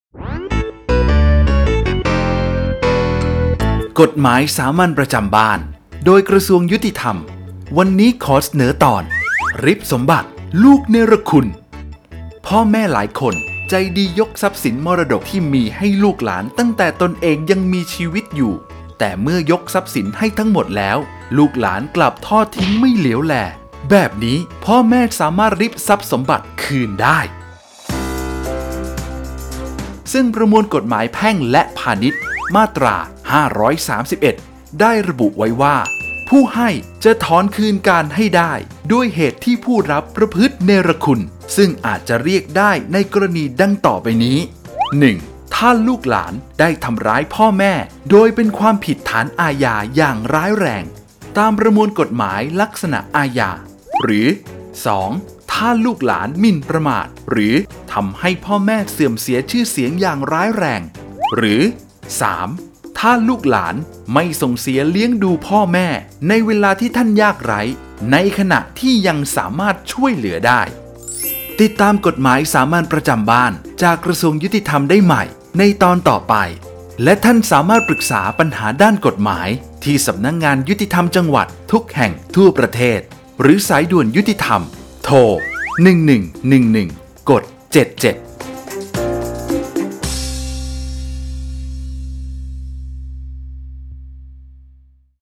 กฎหมายสามัญประจำบ้าน ฉบับภาษาท้องถิ่น ภาคกลาง ตอนริบสมบัติลูกเนรคุณ
ลักษณะของสื่อ :   บรรยาย, คลิปเสียง